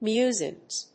/ˈmjuzɪŋz(米国英語), ˈmju:zɪŋz(英国英語)/